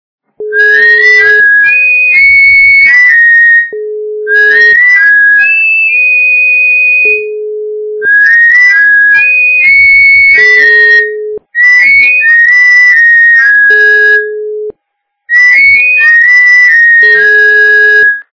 При прослушивании СМС - Свист качество понижено и присутствуют гудки.
Звук СМС - Свист